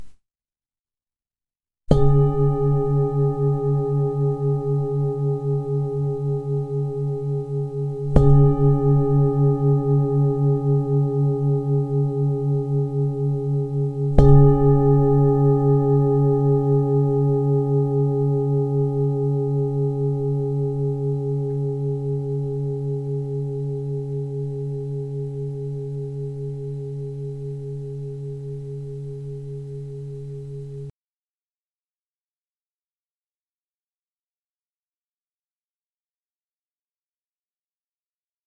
Zdobená tibetská mísa C#3 24,5cm
Nahrávka mísy úderovou paličkou:
Jde o ručně tepanou tibetskou zpívající mísu dovezenou z Nepálu.